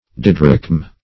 Search Result for " didrachm" : The Collaborative International Dictionary of English v.0.48: Didrachm \Di"drachm\, Didrachma \Di*drach"ma\, n. [Gr.